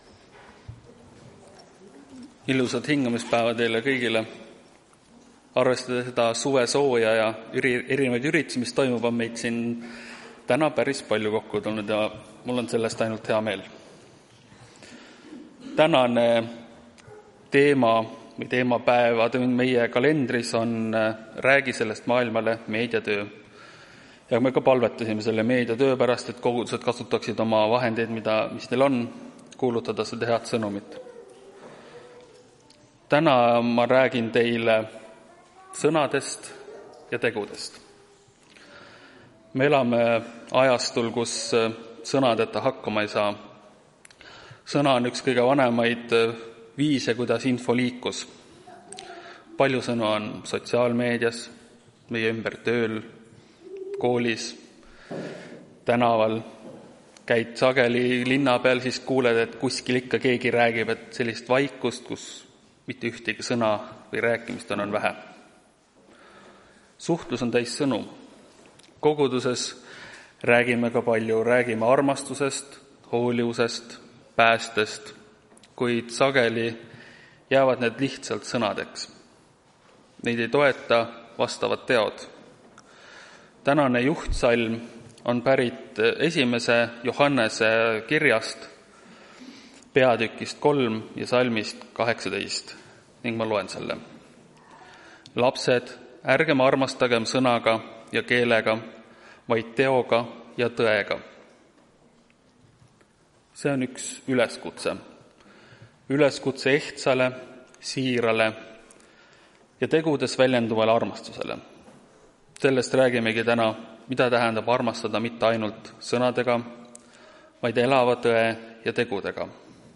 Tartu adventkoguduse 19.07.2025 hommikuse teenistuse jutluse helisalvestis.